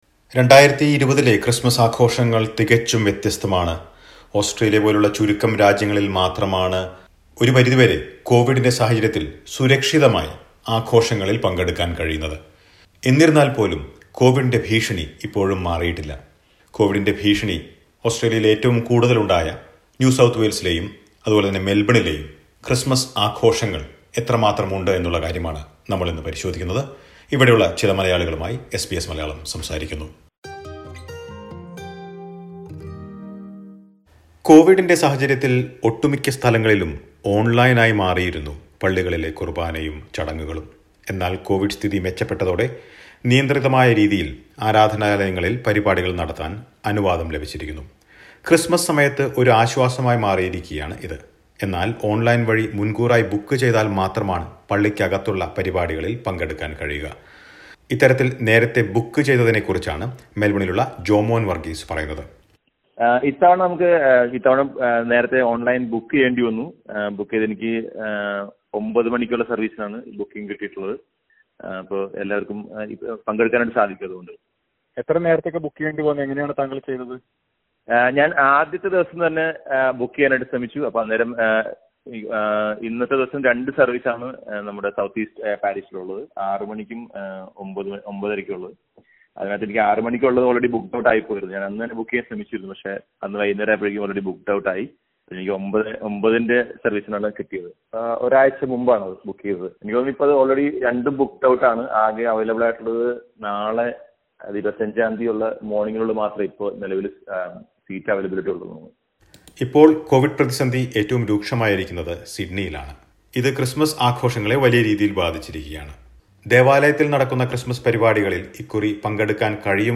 Australia is set for Christmas celebrations with restrictions to ensure safety. Listen to a report.